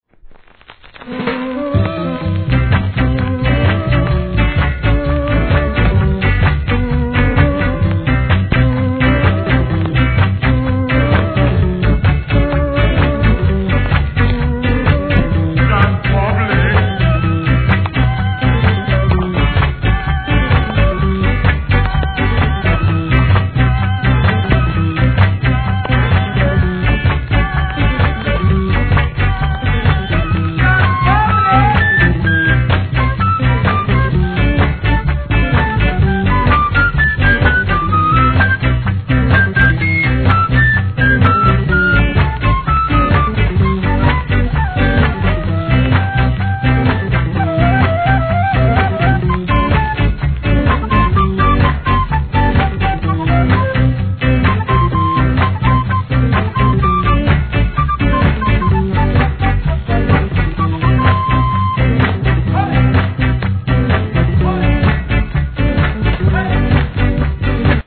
1. REGGAE
フルートの気持いい1969年ROCK STEADY INST物.!